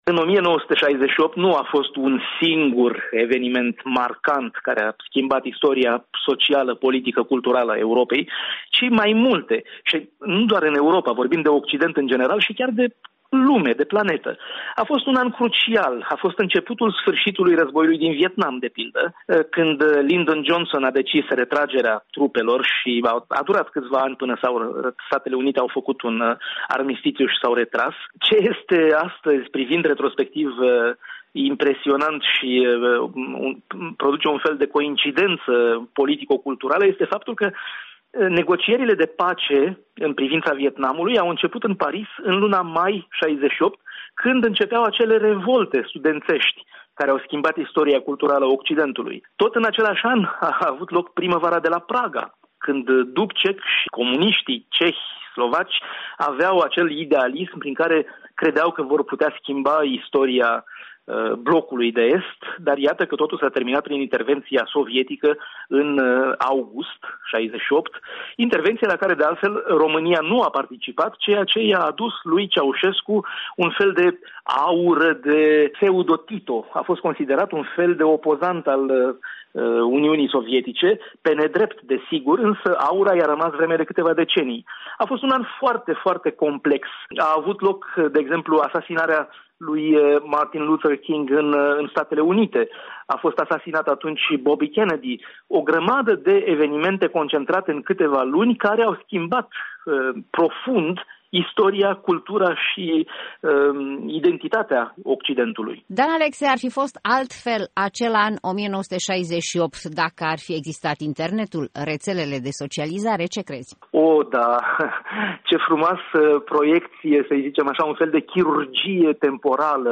De vorbă cu